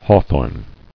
[haw·thorn]